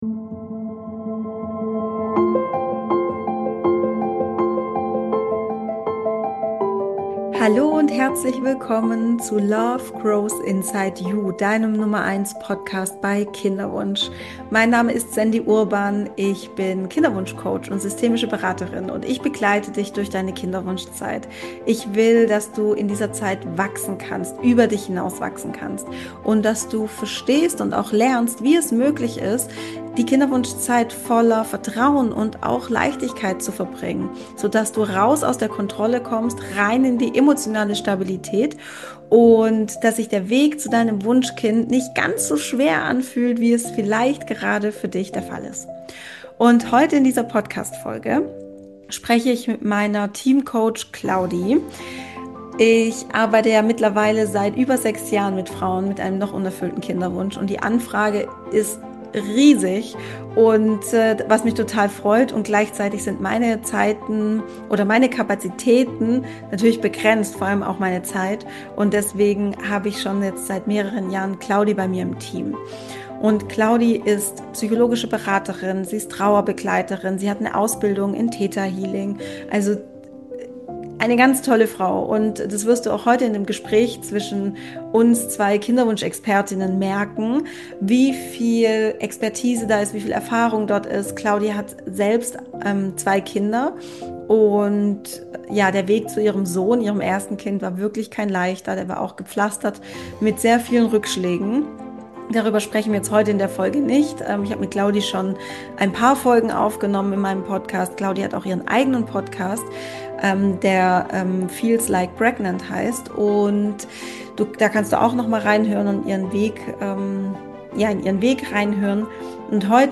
Hoffnung, Angst & Schicksal: Kinderwunsch Q&A mit 2 Expertinnen